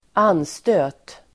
Ladda ner uttalet
Uttal: [²'an:stö:t]